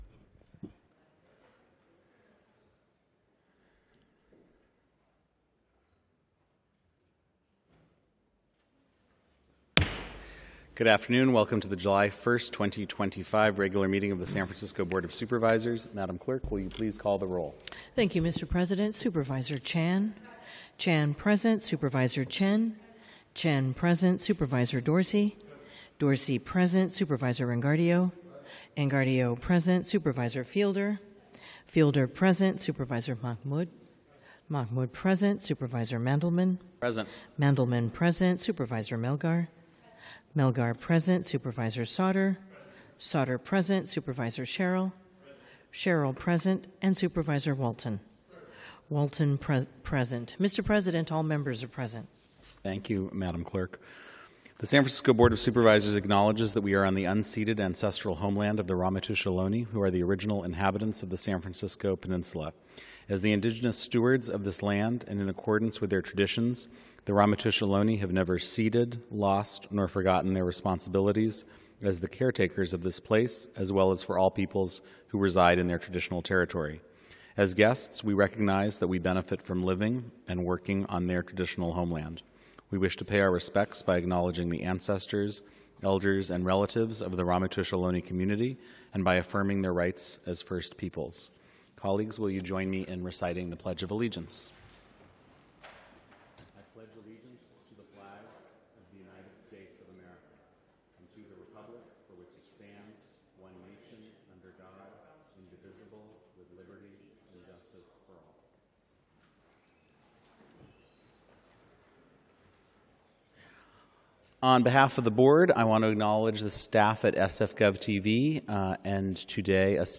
BOS Board of Supervisors - Regular Meeting - Jul 01, 2025